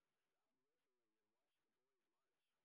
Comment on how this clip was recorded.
sp23_street_snr30.wav